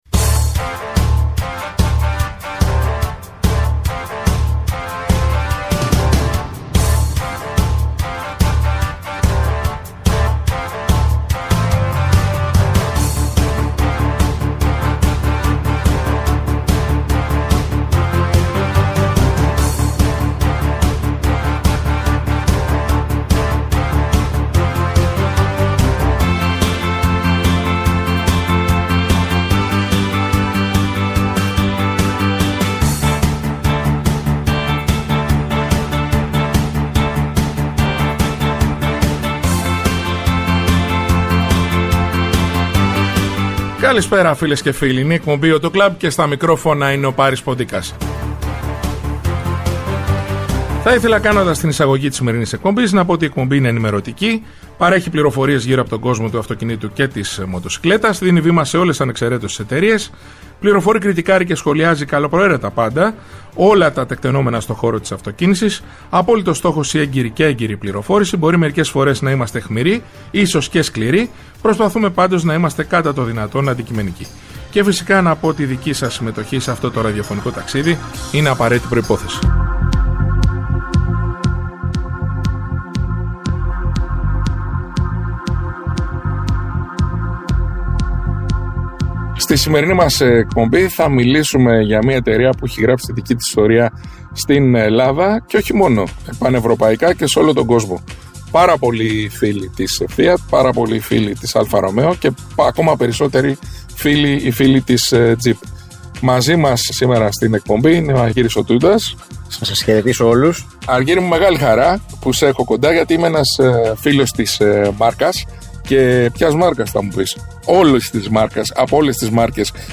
Η εκπομπή «AUTO CLUB» είναι ενημερωτική, παρέχει πληροφορίες γύρω από τον κόσμο του αυτοκινήτου και της μοτοσικλέτας, δίνει βήμα σε όλες ανεξαιρέτως τις εταιρείες, φιλοξενεί στο στούντιο ή τηλεφωνικά στελέχη της αγοράς, δημοσιογράφους αλλά και ανθρώπους του χώρου.
Κριτικάρει και σχολιάζει καλοπροαίρετα πάντα όλα τα τεκταινόμενα στο χώρο της αυτοκίνησης, με απόλυτο στόχο την έγκαιρη και έγκυρη πληροφόρηση για τους ακροατές, με «όπλο» την καλή μουσική και το χιούμορ.